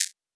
LittleNoise.wav